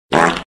Fart Sound Effect Free Download
Fart